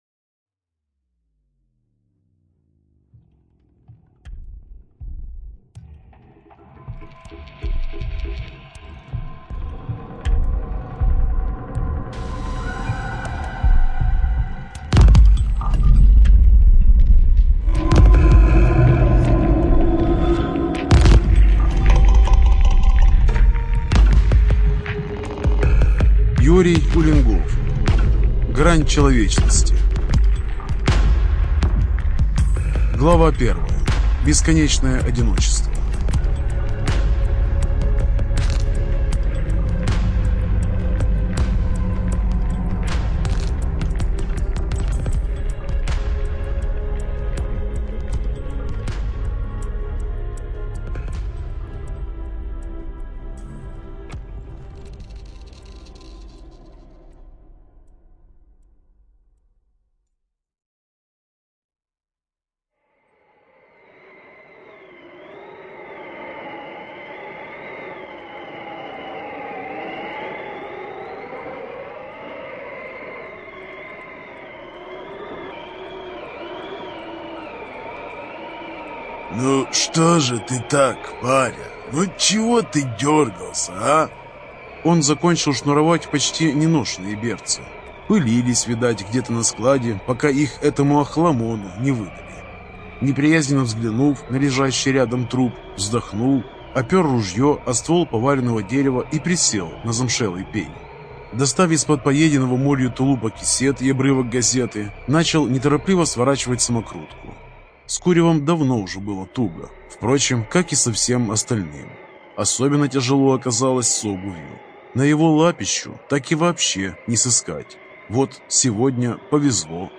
ЖанрФантастика, Боевики